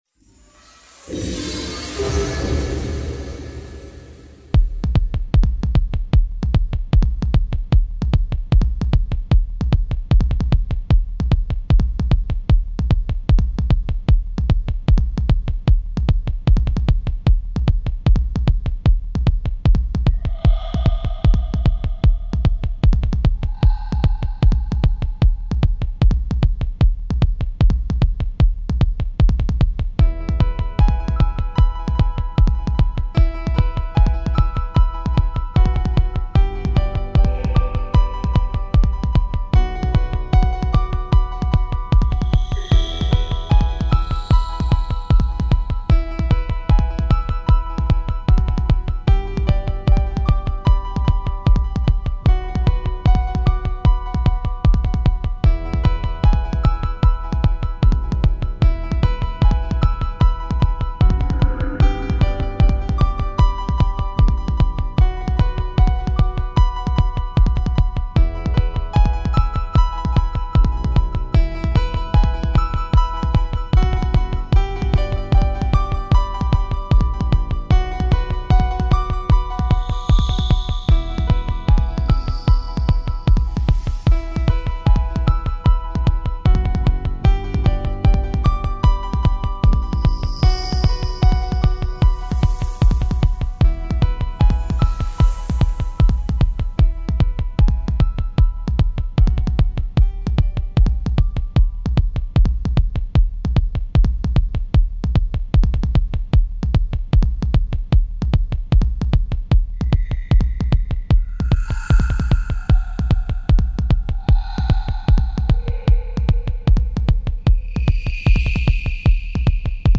piano riff